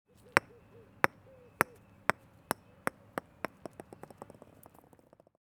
golf-ball-bounce.wav